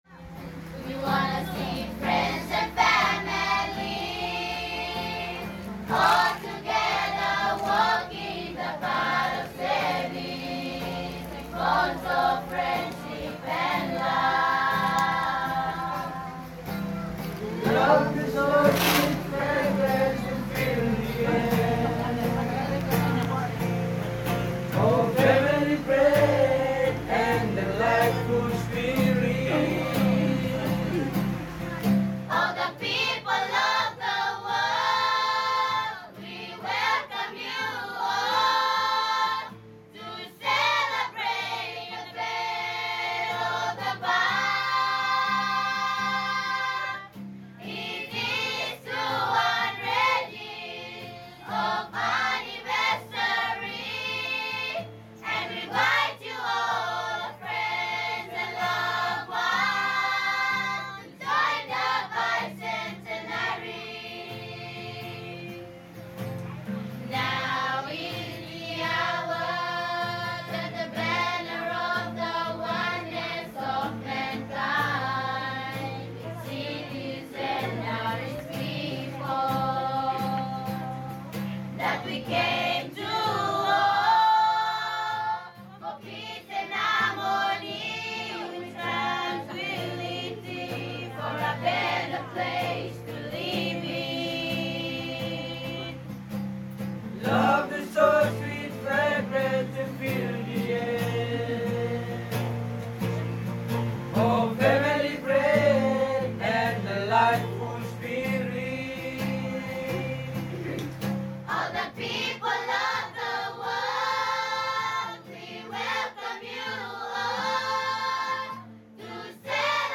In heartfelt harmony, children and youth of the Solomon Islands sing their invitation to their friends, and loved ones to join the celebrations of bicentenary of the birth of the Báb.